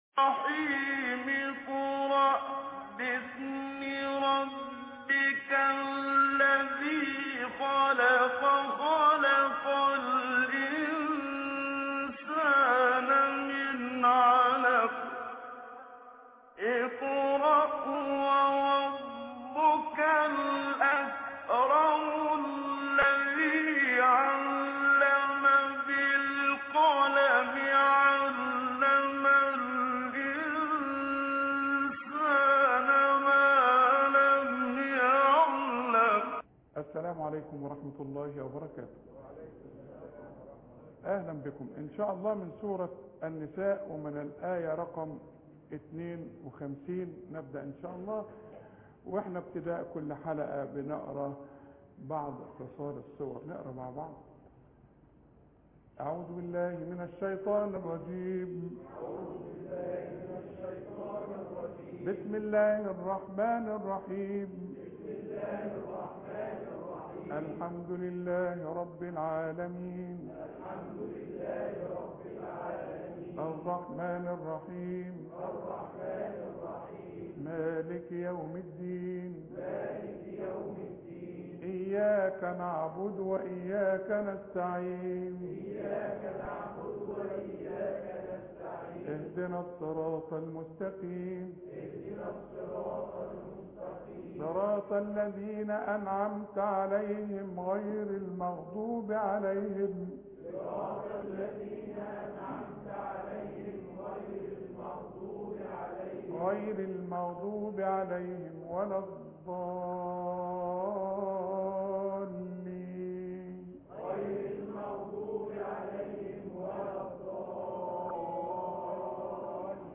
قراءة من الاية 51 فى سورة النساء